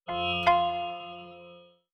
SFX_Menu_Confirmation_05.wav